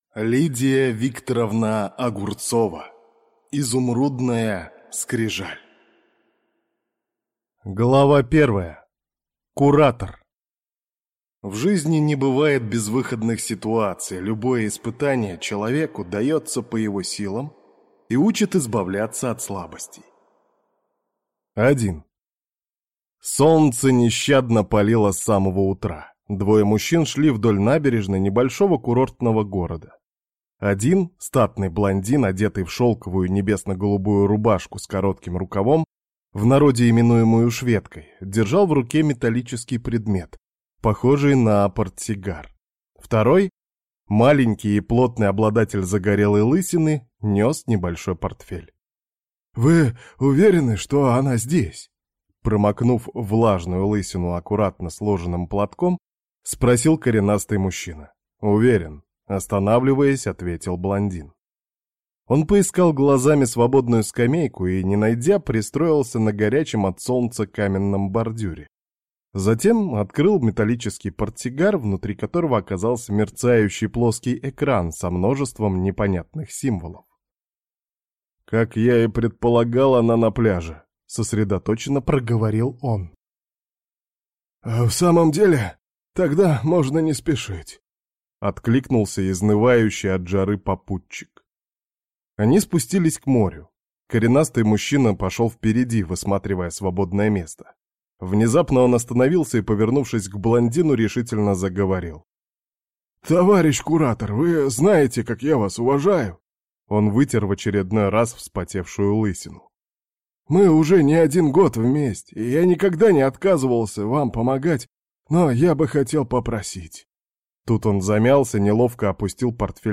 Аудиокнига Изумрудная скрижаль | Библиотека аудиокниг